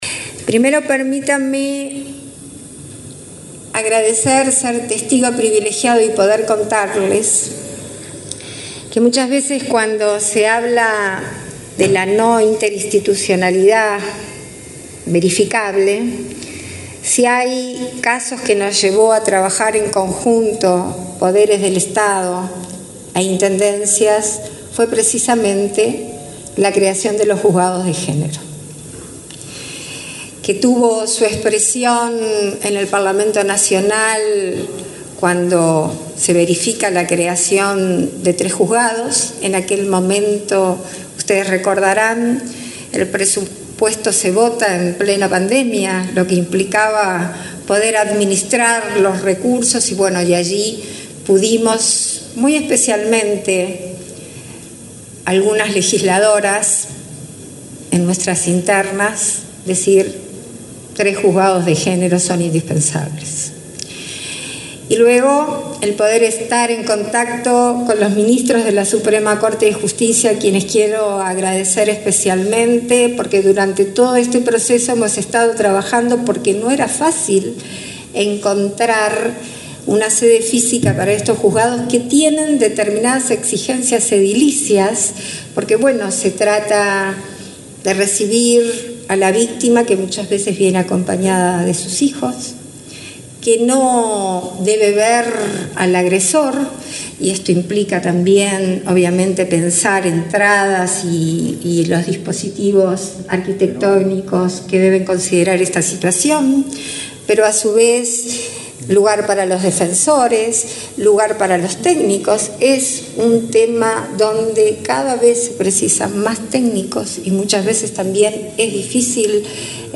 Palabras de la presidenta en ejercicio, Beatriz Argimón
Palabras de la presidenta en ejercicio, Beatriz Argimón 16/08/2024 Compartir Facebook X Copiar enlace WhatsApp LinkedIn Este viernes 16, la presidenta de la República en ejercicio, Beatriz Argimón, participó en la inauguración de dos juzgados especializados en violencia de género, doméstica y sexual en el departamento de Rivera.